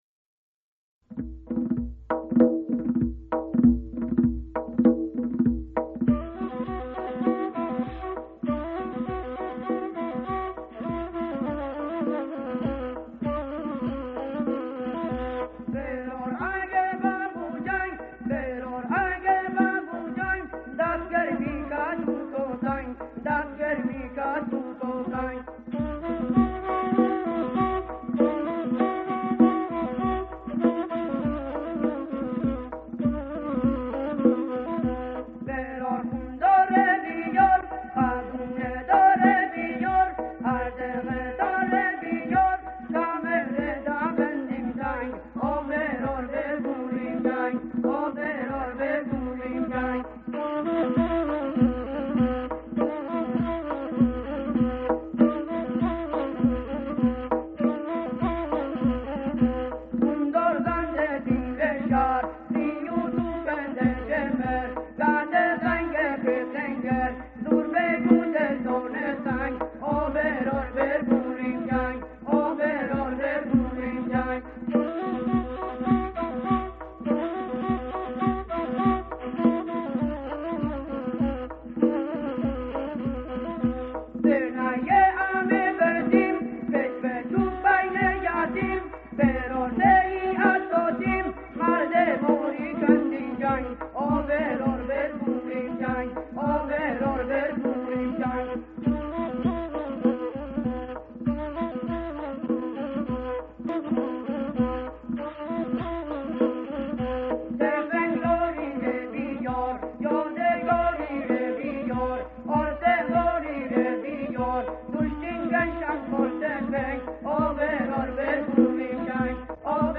سرود محلی مازندرانی با مضمون دفاع مقدس/خواننده
شیوه اجرا: دونوازی